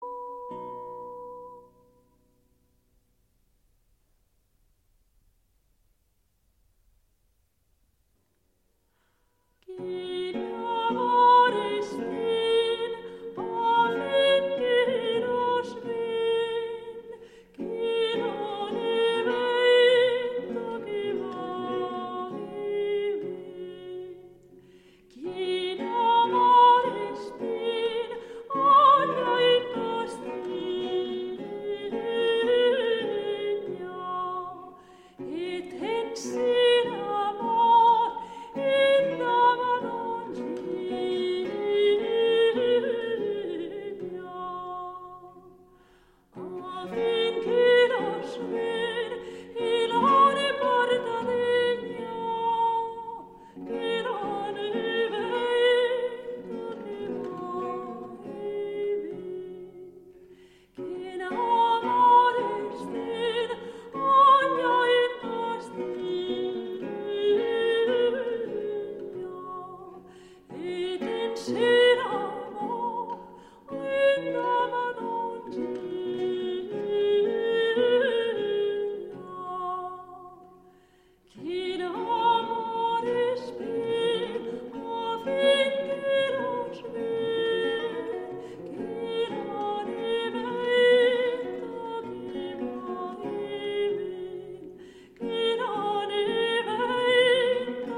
Villancico
~1400 - ~1800 (Renaissance)